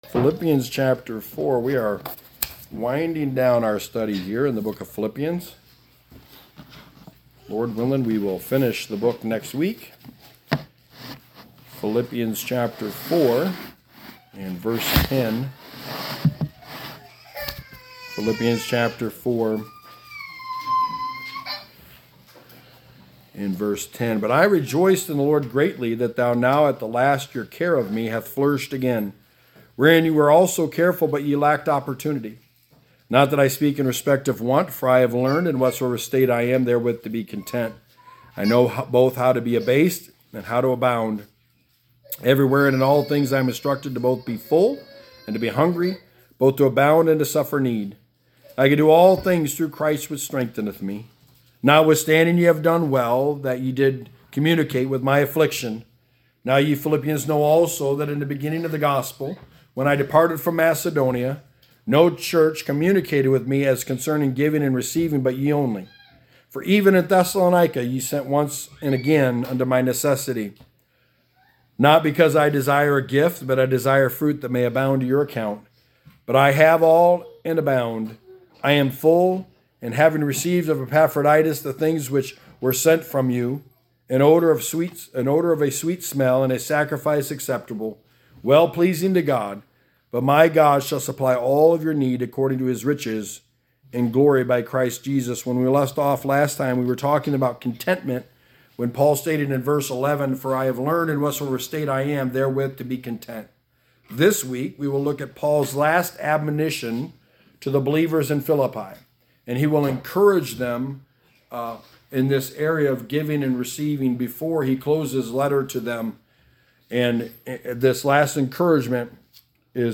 Sermon 33: The Book of Philippians: Fruit to Your Account
Passage: Philippians 4:10-19 Service Type: Sunday Morning